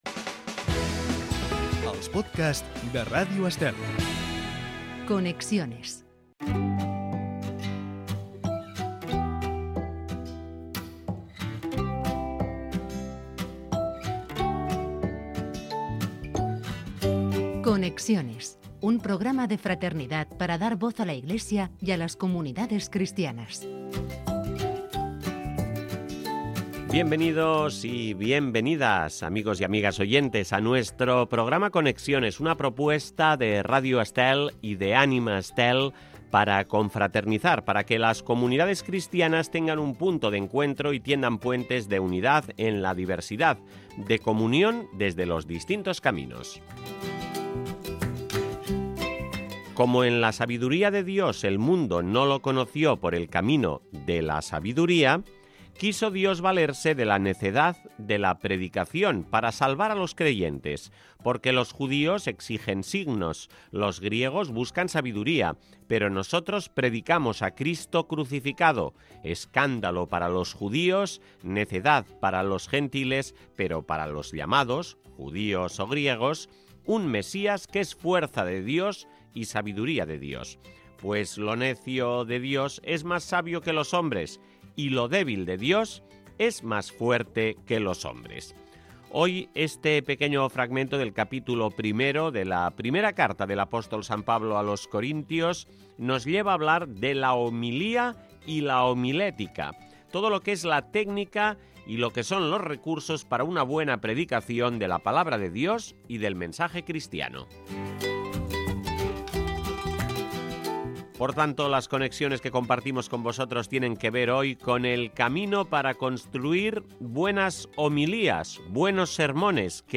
Podcast de contenido religioso y social con un reportaje sobre comunidades cristianas o vínculos entre distintas realidades y una entrevista en profundidad.